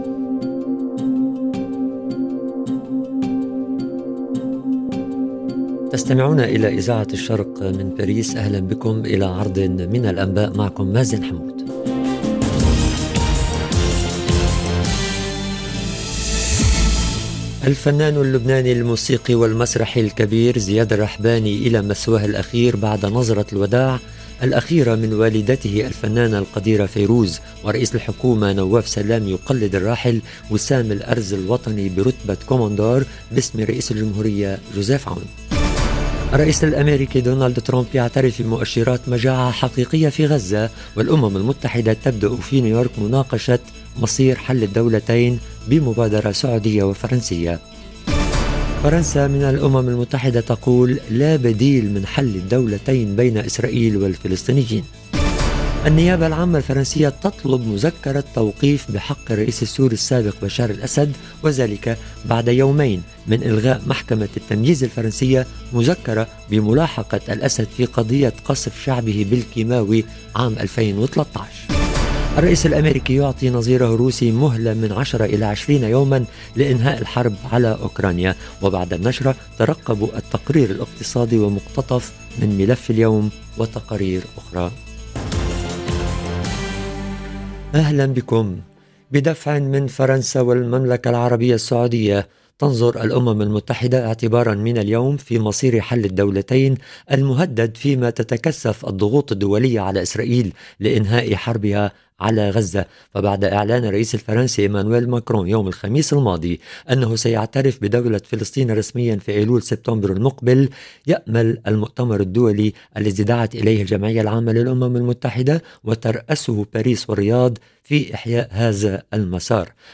نشرة أخبار المساء: لبنان يودع زياد الرحباني، ترامب يعترف بمؤشرات المجاعة في غزة - Radio ORIENT، إذاعة الشرق من باريس